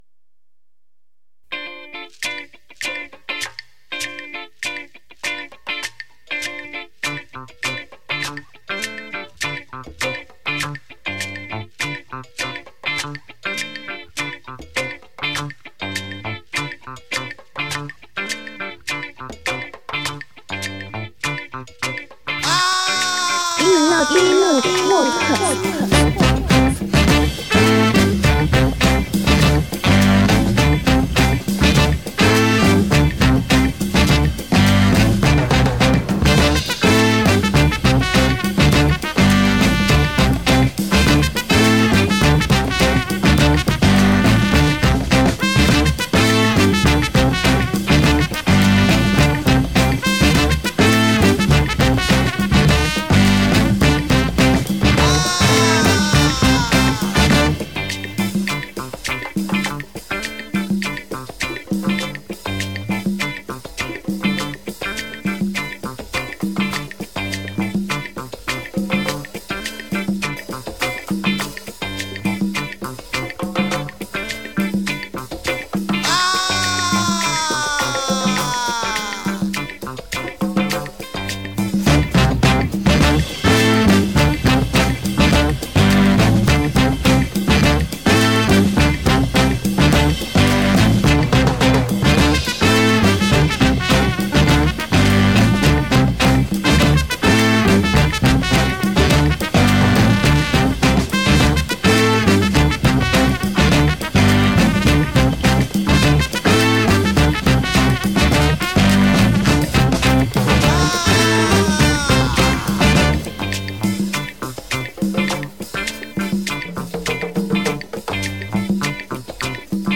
1 hour long Afrobeat mix